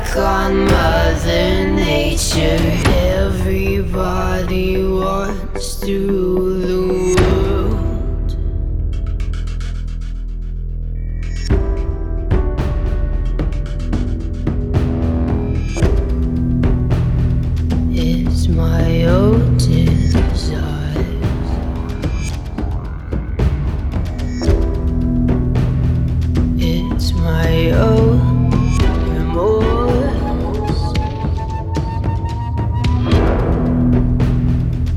Жанр: Альтернатива